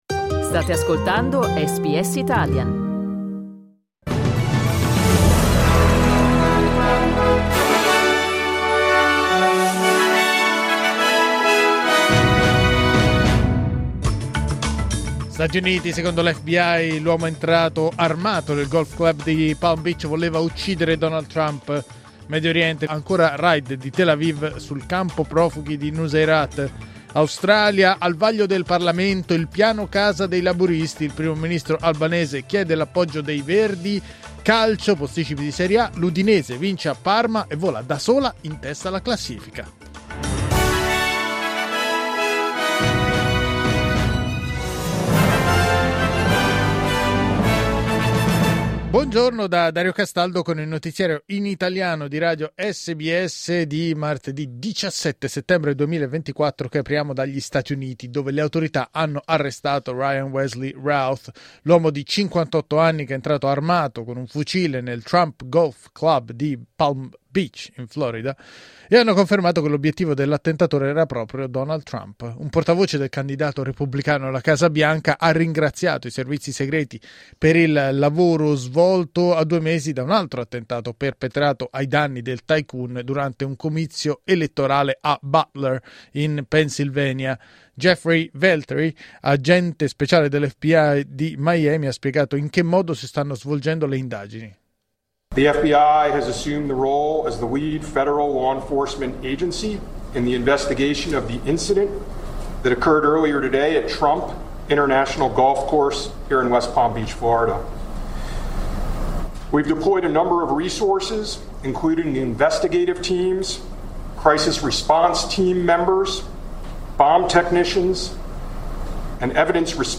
Giornale radio martedì 17 settembre 2024
Il notiziario di SBS in italiano.